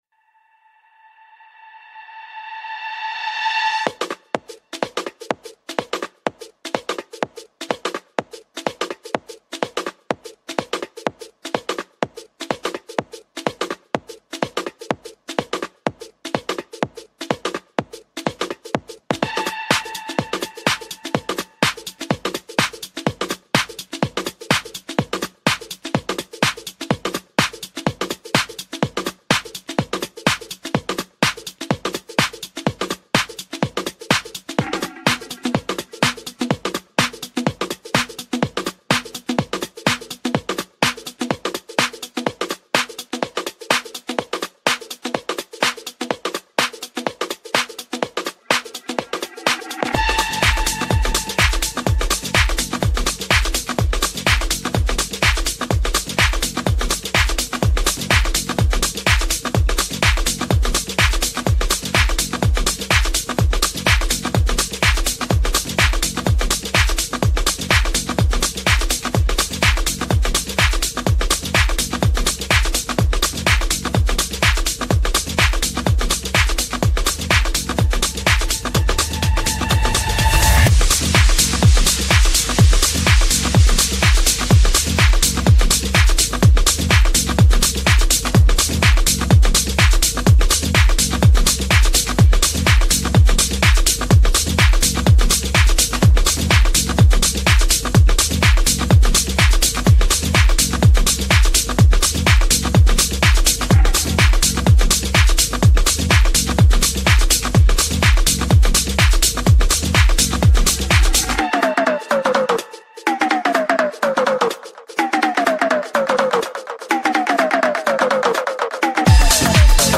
Tech House
BPM - 125